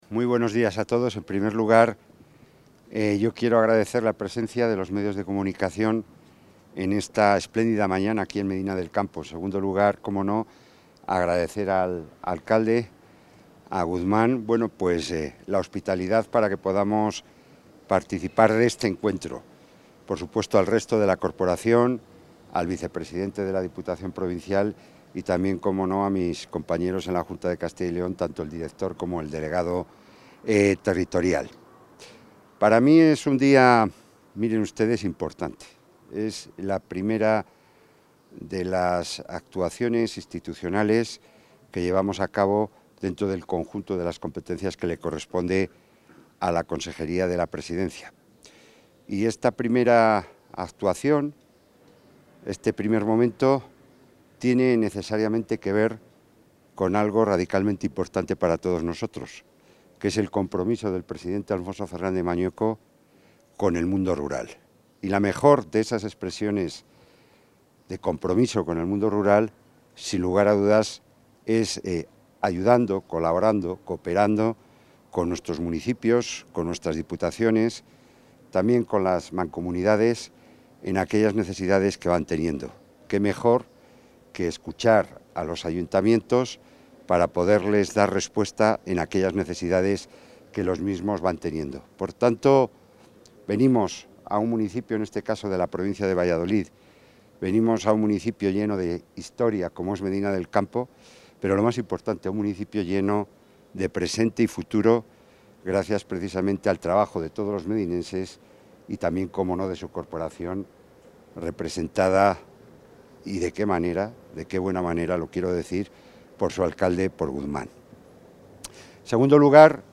Intervención del consejero de la Presidencia.